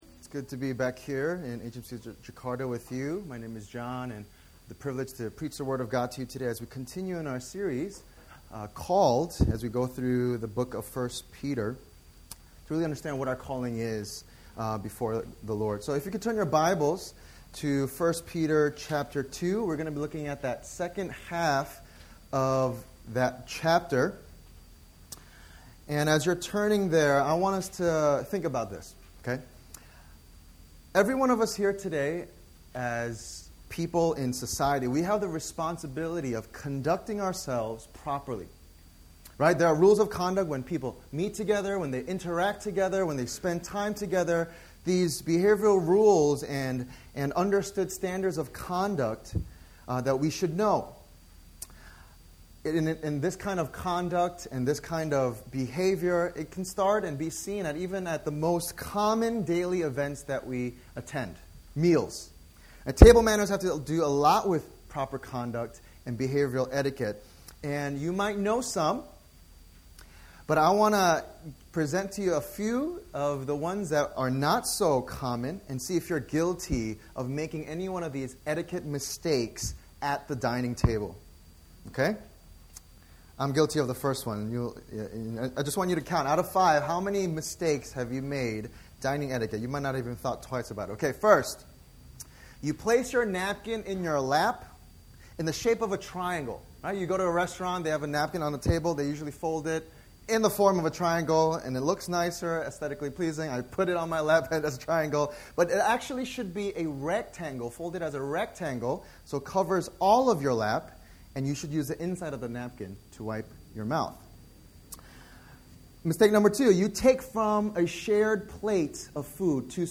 In this sermon series, we’ll be looking through Apostle Peter’s First Letter to different churches in the first century amidst a time of great suffering and persecution, and we’ll see different aspects of what God calls us to as disciples of Jesus Christ: 1: Experiencing Salvation 2: Growing Up Into Salvation 3: Knowing Who We Are Together 4: Living as Servants 5: Living as Wives & Husbands 6: Suffering for Doing Good 7: Glorifying God in Everything 8: Suffering as a Christian 9: Standing Firm as the Church